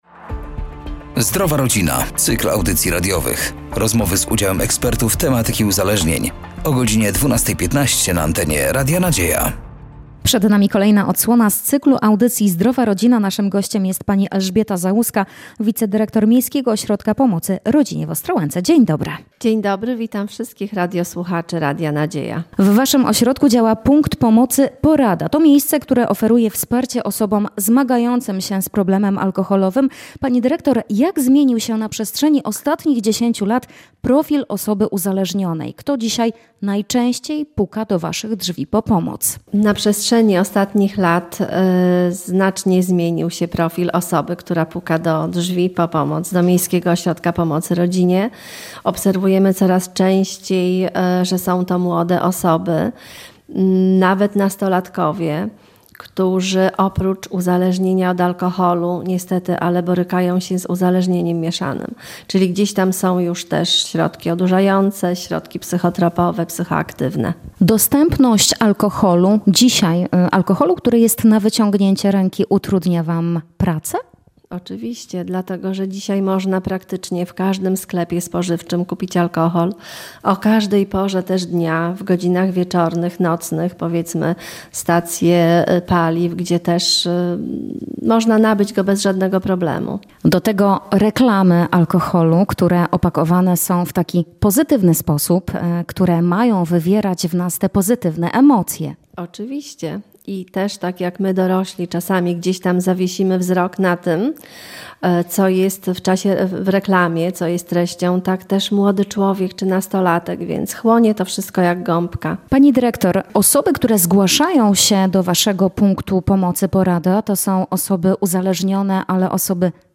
Zapraszamy do wysłuchania kolejnej rozmowy z cyklu audycji ,,Zdrowa Rodzina”.